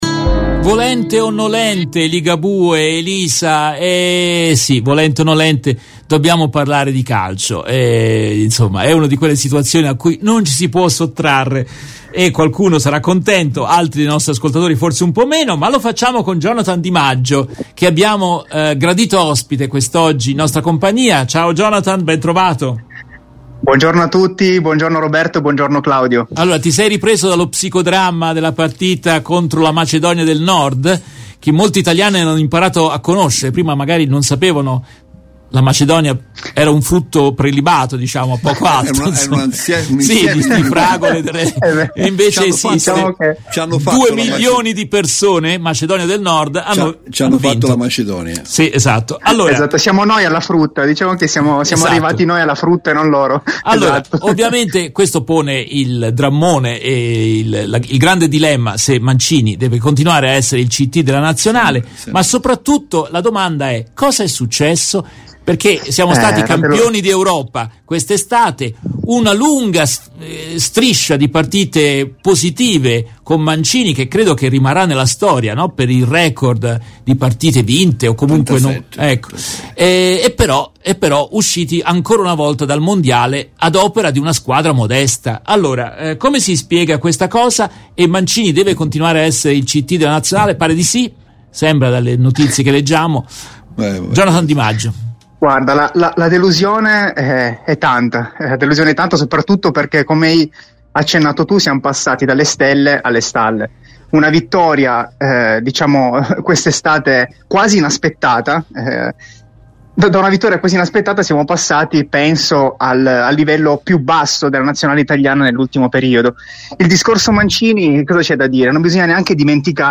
Nel corso della trasmissione in diretta del 28 marzo 2022,